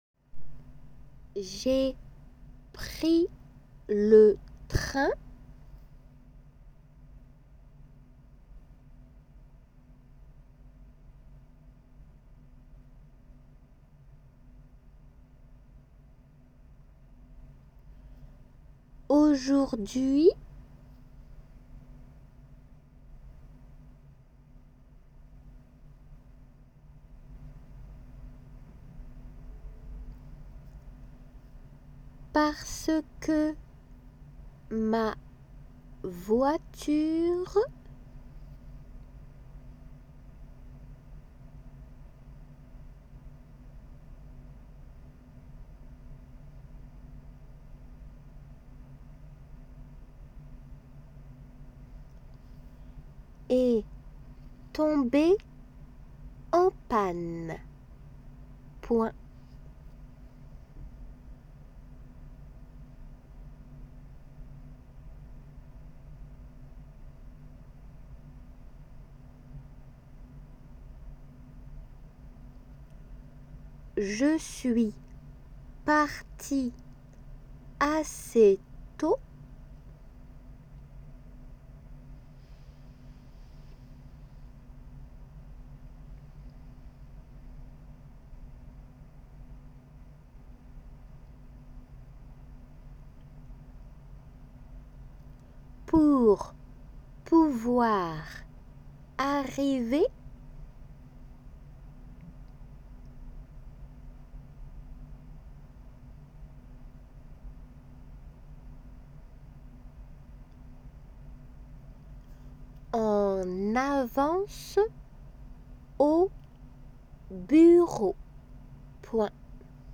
C    女性
T　男性